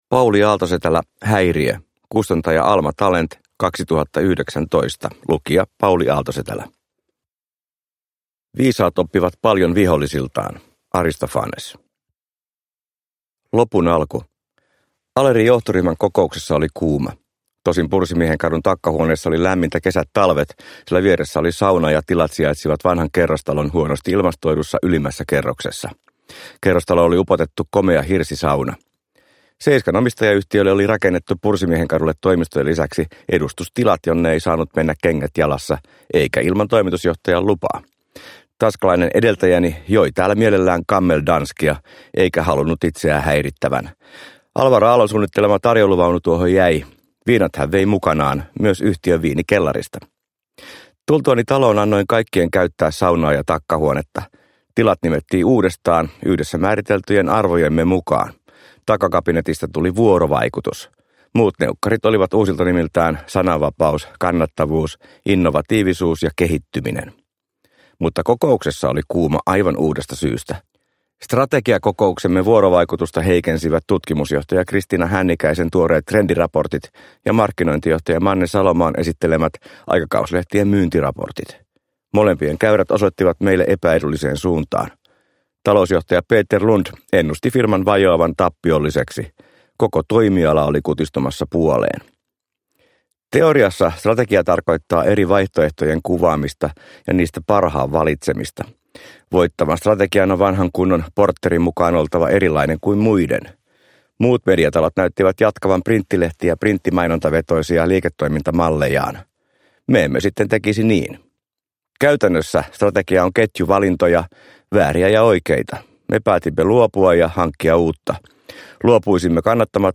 Häiriö – Ljudbok – Laddas ner